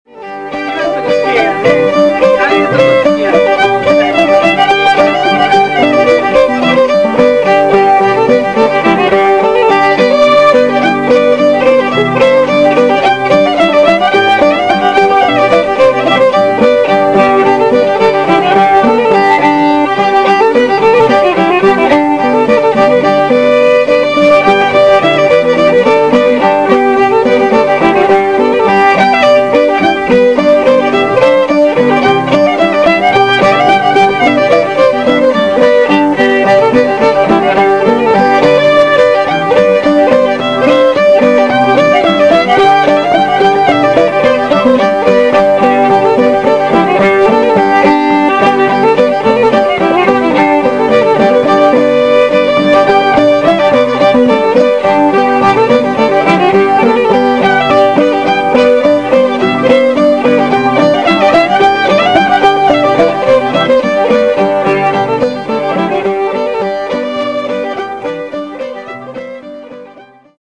** Old-Time JAM **
* 2004 TAKARAZUKA BLUEGRASS FEST.